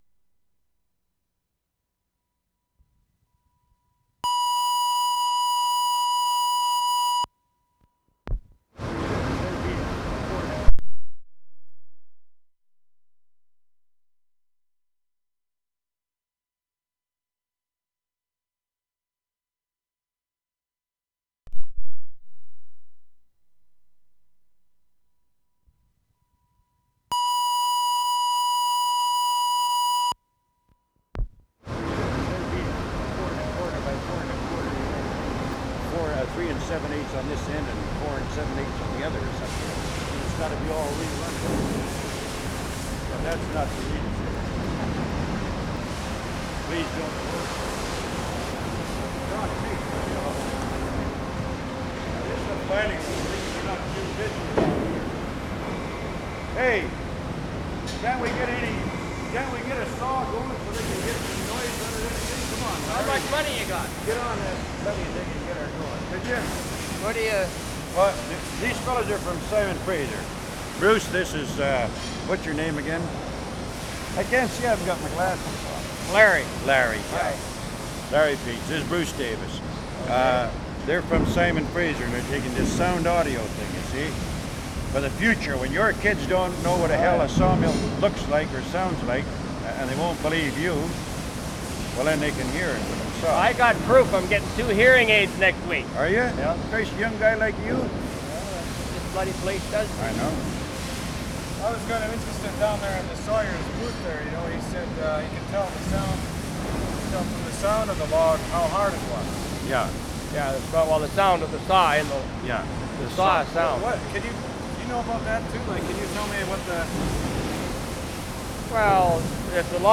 WORLD SOUNDSCAPE PROJECT TAPE LIBRARY
CHEMAINUS, BC November 1, 1976
The discussion is about the sounds the saw makes. There are two men who try to talk over each other.
The recordist is showing how the tape deck works.
3. There is an engine hum and steam releases at regular intervals.
*16:00 shift whistle blasts.
The recordist lowers one channel because it is drowning out the voices.